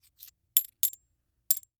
household
Coins Movement Counting Multiple 2